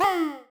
caw.ogg